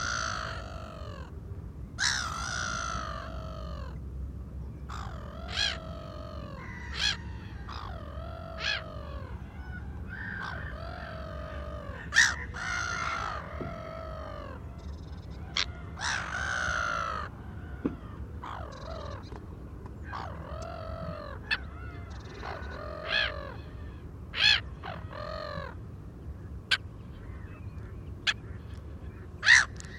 Mouette rieuse - Mes zoazos
mouette-rieuse.mp3